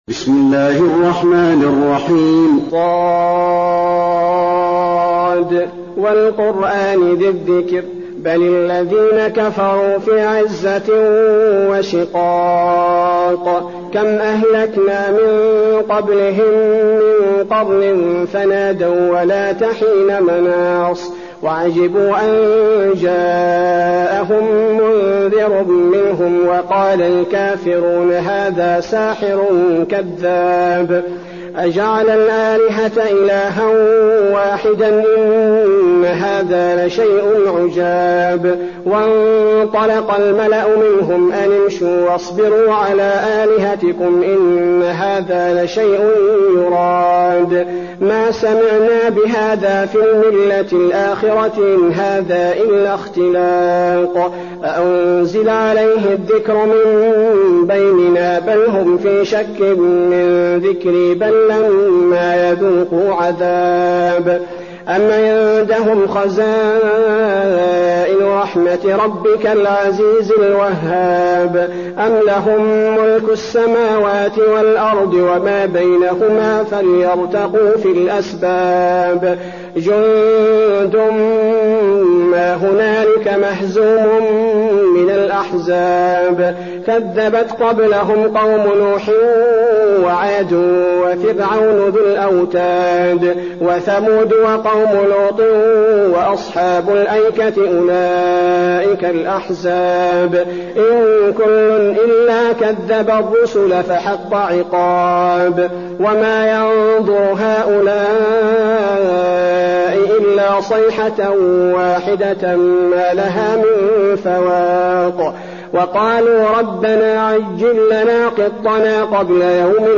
المكان: المسجد النبوي ص